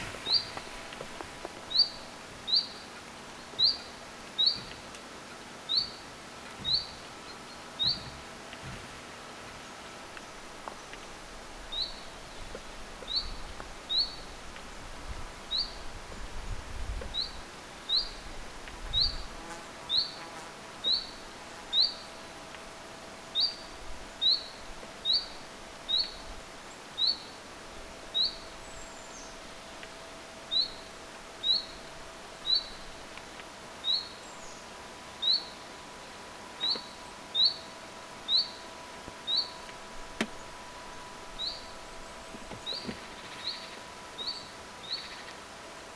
päivän ensimmäinen vit-tiltaltti
ensimmainen_vit-tiltaltti.mp3